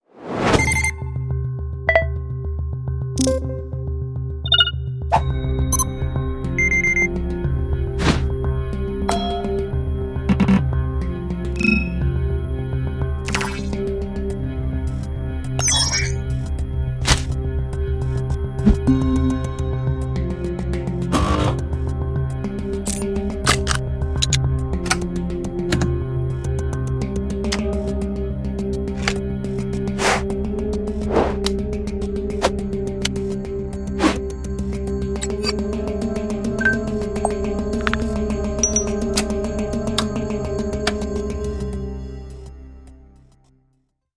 音效素材-极具科幻感的用户UI界面交互操作无损声音678组
它包含678个精心设计的音效，能够为软件、游戏或多媒体项目中的各种UI操作，如按钮点击、菜单切换、消息提示等，增添生动的听觉反馈。
素材库涵盖了按钮、点击声、提示音、通知音、滑动以及嗖嗖声等多种类型，旨在通过声音细节提升用户的操作体验，让界面互动更具质感。
SmartSoundFX-User-Interaction-Preview.mp3